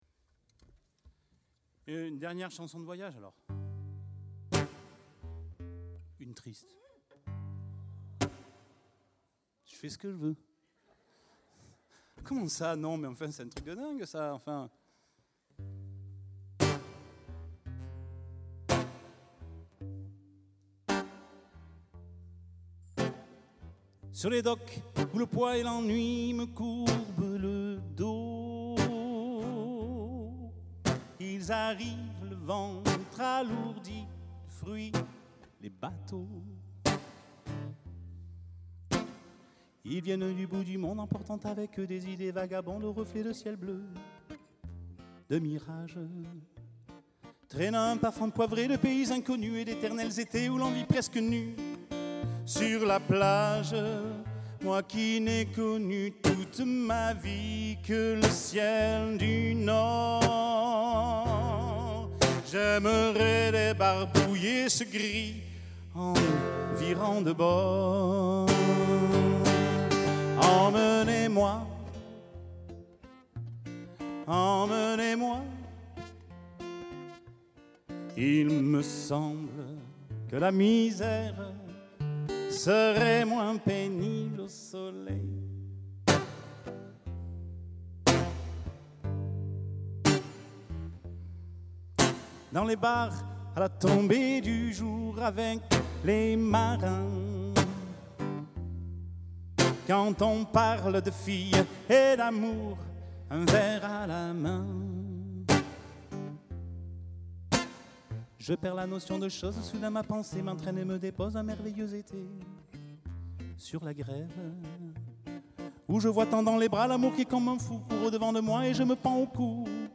Conte enchanté autour du répertoire classique de la chanson française
Synopsis : Une guitare, une voix et « en voiture Simone …